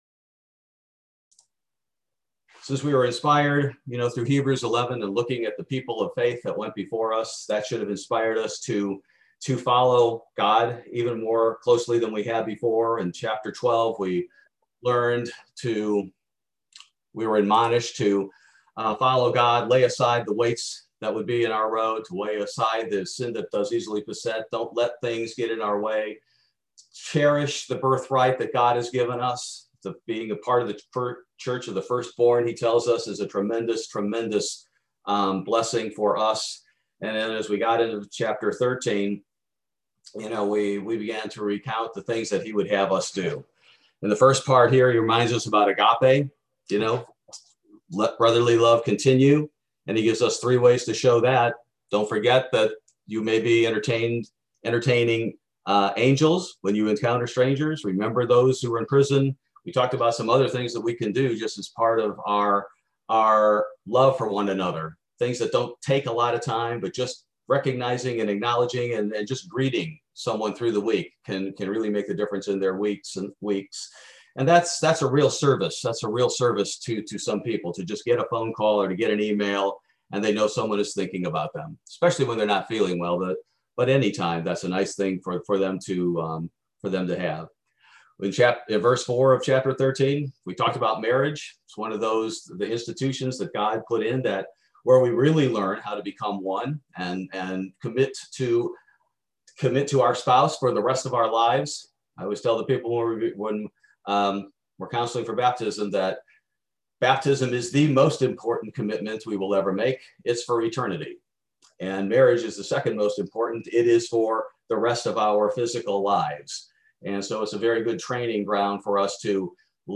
Bible Study: March 17, 2021